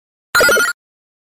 Right Answer.mp3